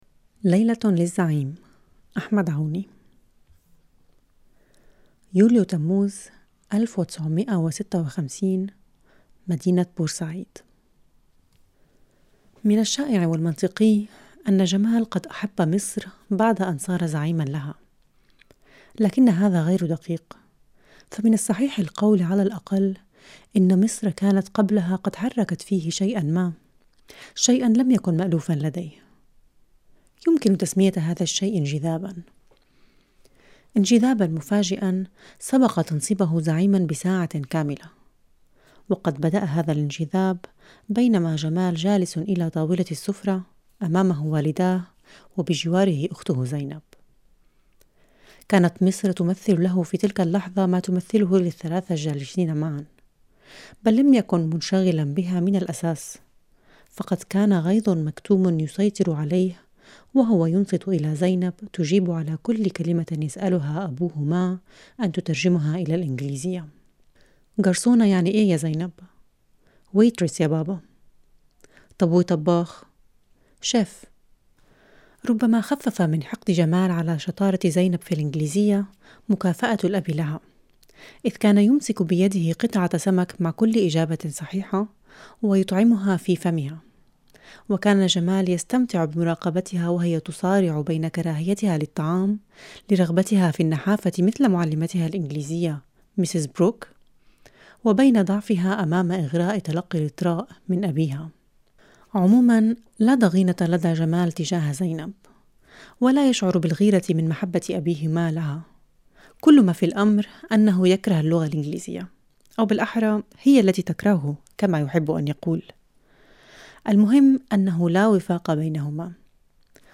Text anhören, vorgelesen von Melika Foroutan: Juli 1956, Port Said 1 Es war durchaus nachvollziehbar, dass Gamal sich in Ägypten verliebte, nachdem er zum Anführer des Landes ernannt worden war.